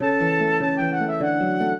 flute-harp
minuet1-11.wav